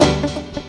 PIANOCHORD-R.wav